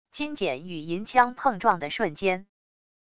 金锏与银枪碰撞的瞬间wav音频